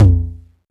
cch_perc_tom_low_womp.wav